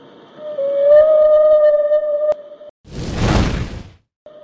bird04.mp3